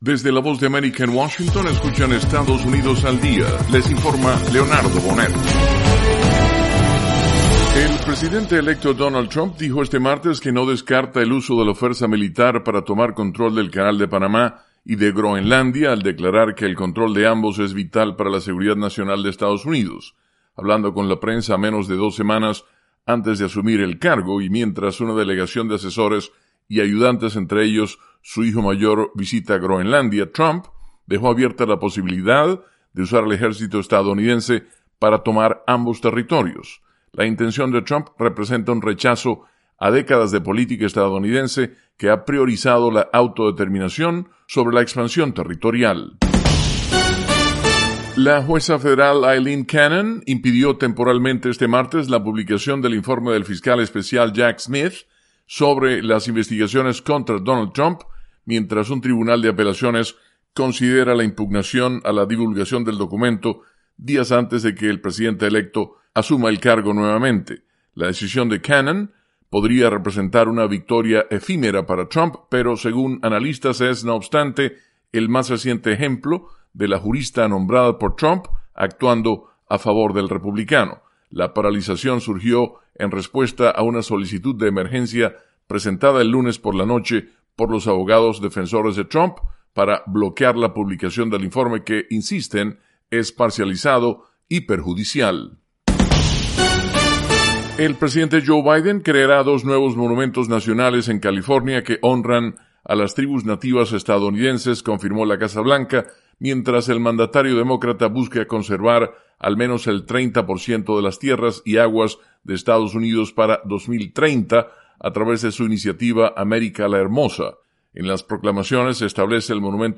Estados Unidos al Día: Con algunas de las noticias nacionales más importantes de las últimas 24 horas.